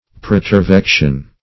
Search Result for " pretervection" : The Collaborative International Dictionary of English v.0.48: Pretervection \Pre`ter*vec"tion\, n. [L. praetervectio, fr. praetervehere to carry beyond.